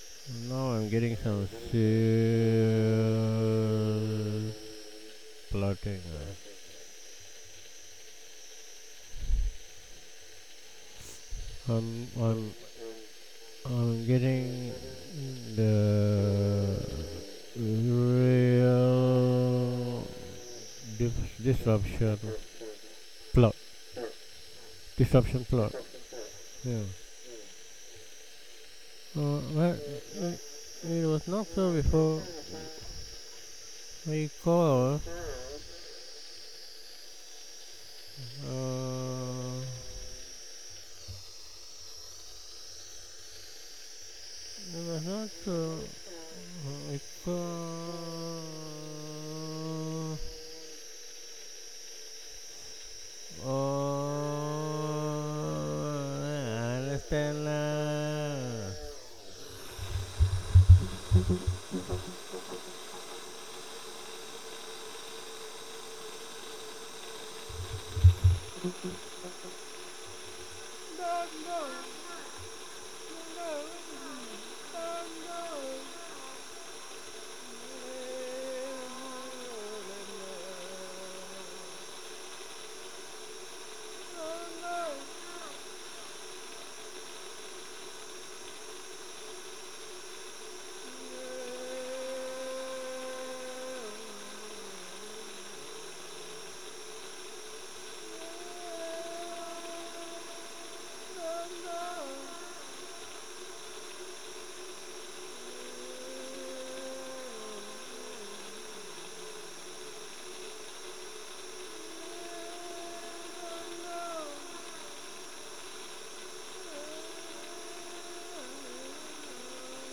recording hdsdr web disruptive the disruptive 31 oct 2015